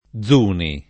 [ +z2 ni ]